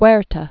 (wĕrtə, -tä), Victoriano 1854-1916.